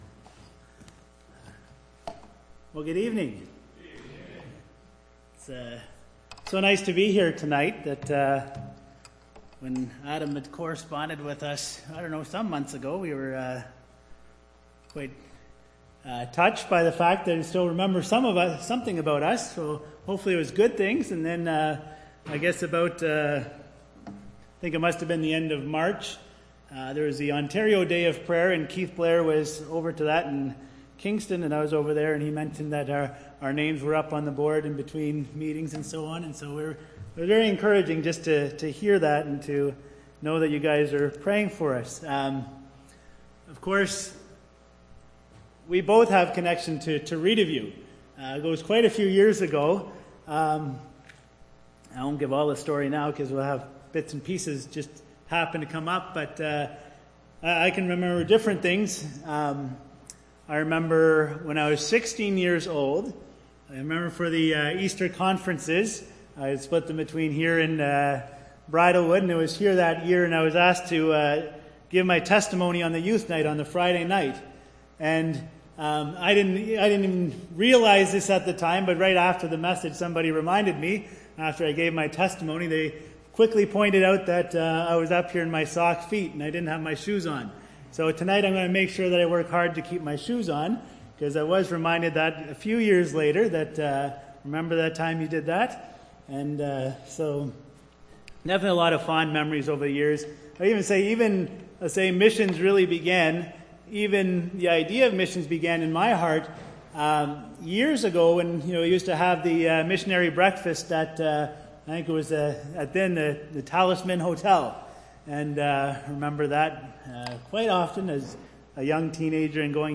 Missions update Preacher
Service Type: Sunday PM